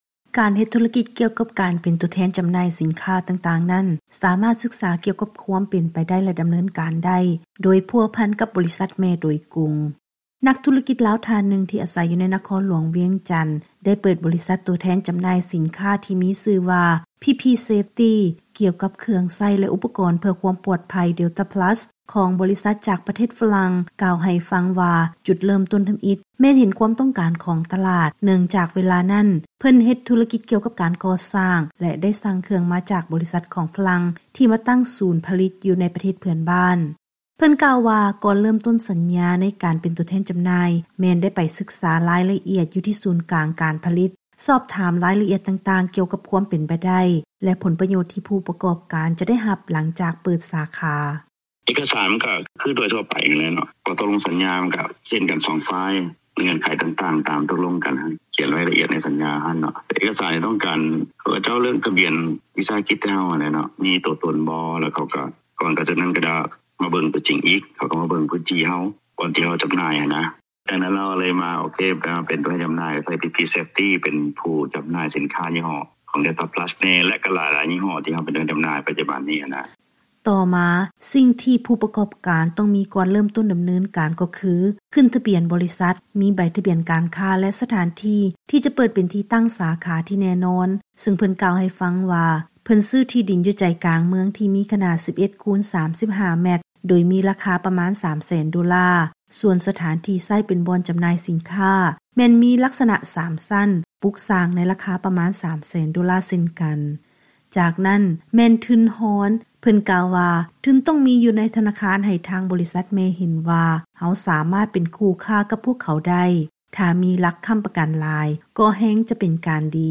ເຊີນຮັບຟັງລາຍງານກ່ຽວກັບ ການເຮັດທຸລະກິດເປັນຕົວແທນຈໍາໜ່າຍ ສິນຄ້ານໍາເຂົ້າ.